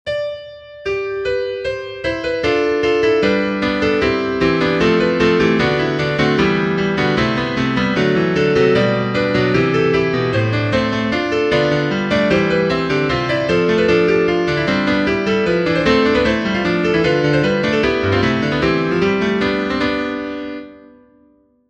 GLORY_BE_TO_THE_FATHER_tutti.mp3